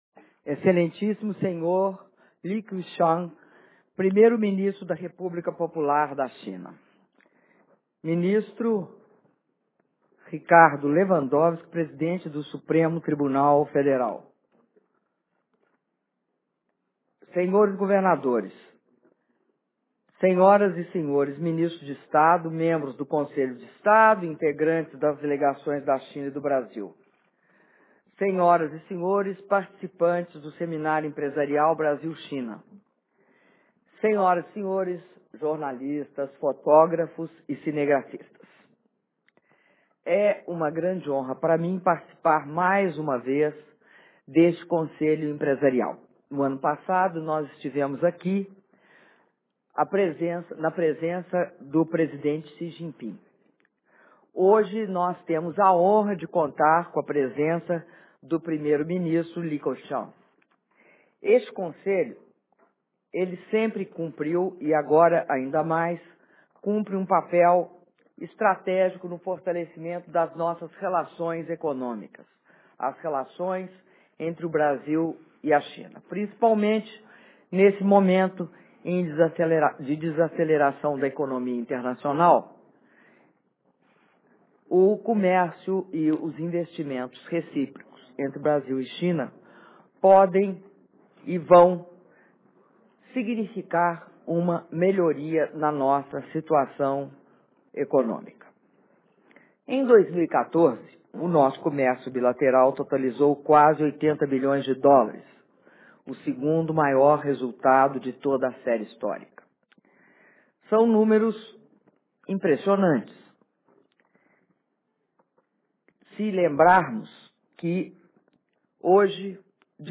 Áudio do discurso da presidenta da República, Dilma Rousseff, durante encerramento do Encontro Empresarial Brasil-China (18min50s) - Palácio Itamaraty